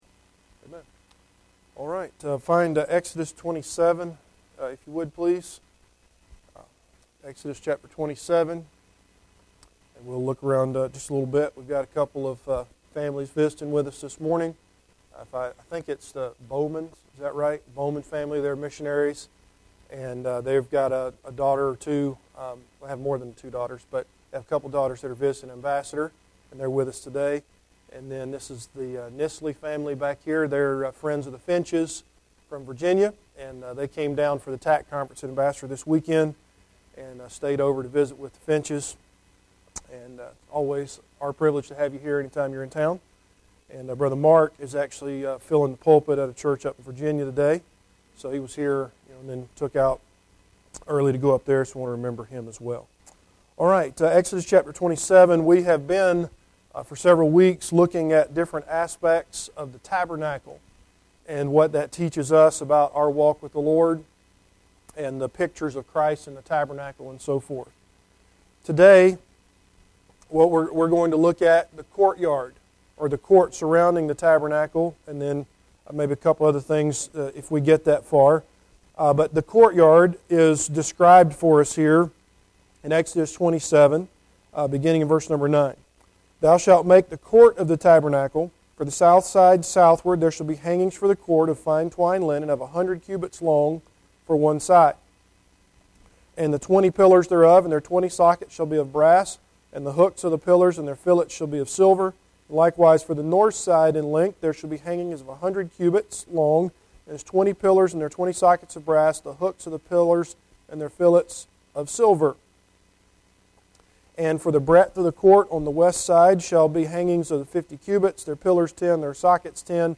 Service Type: Adult Sunday School Class